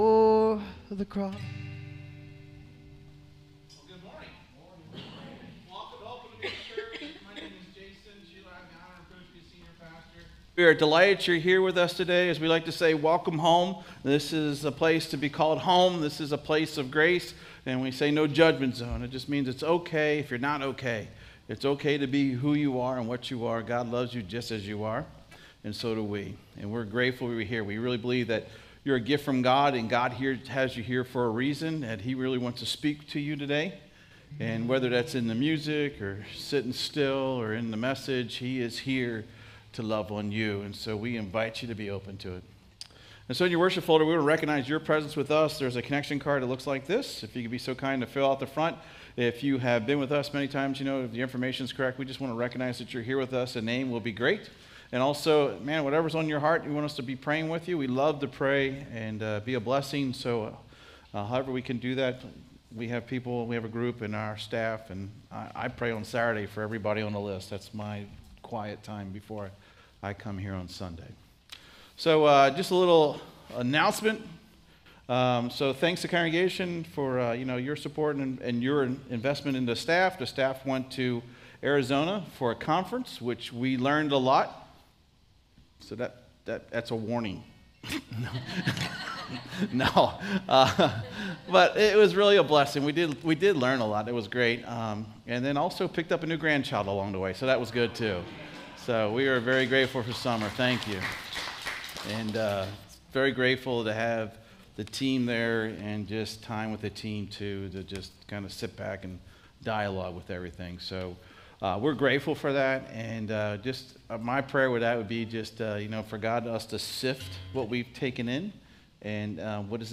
SERMON DESCRIPTION This sermon unpacks Hosea 4 to contrast a true knowledge of God with empty, outward religion.